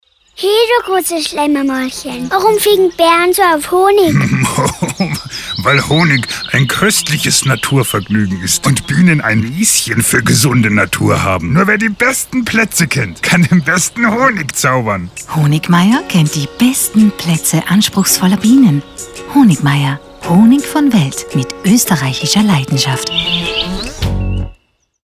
Mit einem komplett neuen Kreativansatz konnte Inspiranto durch Storytelling das Klischee des naschenden Bären im Dialog mit dem entzückenden Schlemmermäulchen wirkungsstark und charismatisch in Szene setzen.
Honigmayr_Radio_Spot1.mp3